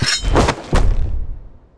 charge_attack_end.wav